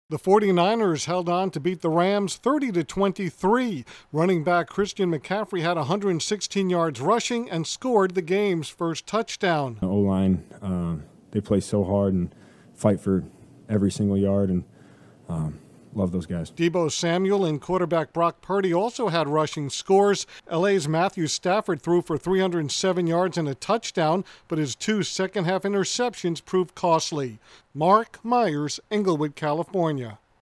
The 49ers extend their regular-season winning streak versus the Rams to nine. Correspondent